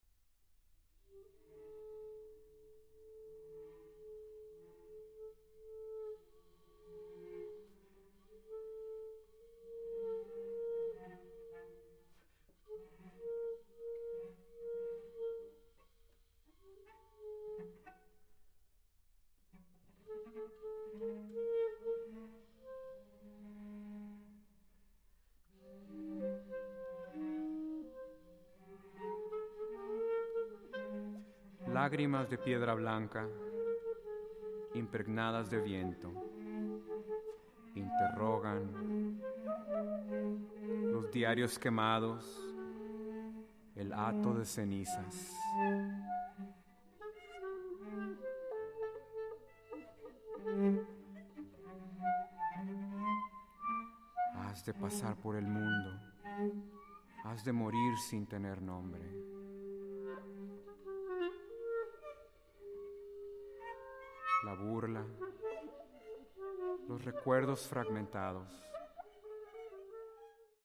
clarinet
cello